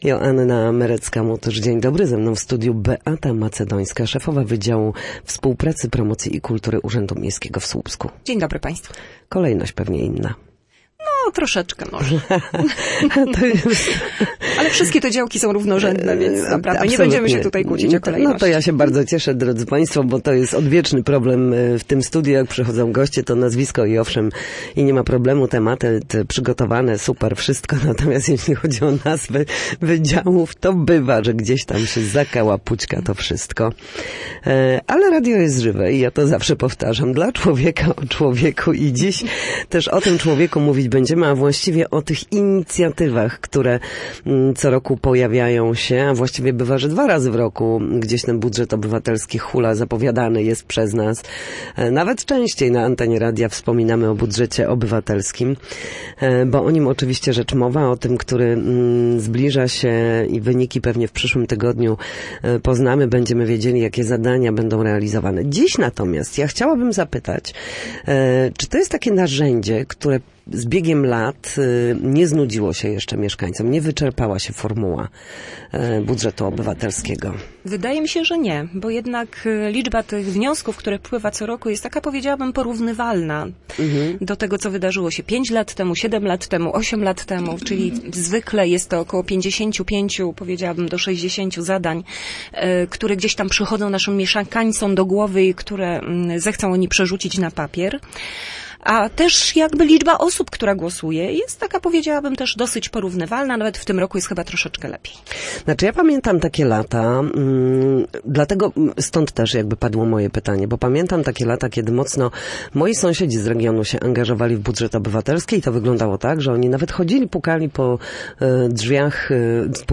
gościni Studia Słupsk